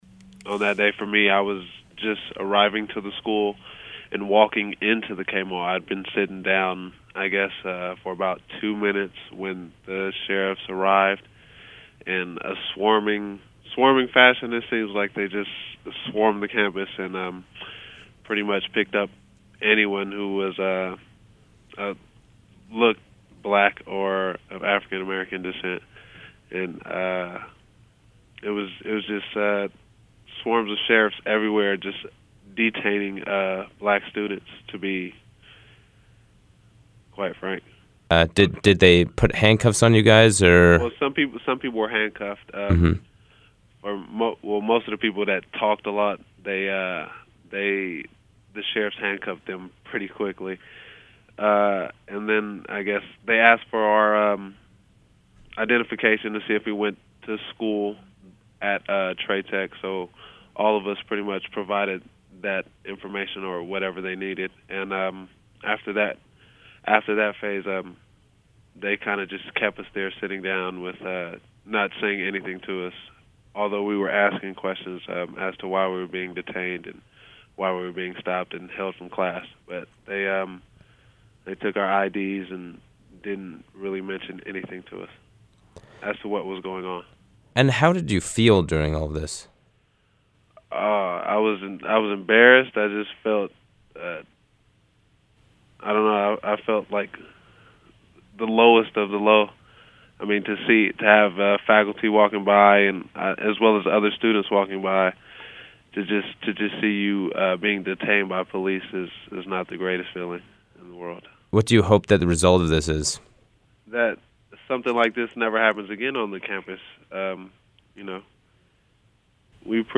Interview with a victim of L.A. Trade Tech College Racial Profiling | USC Annenberg Radio News